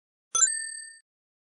Звуки пополнения счета